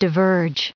244_diverge.ogg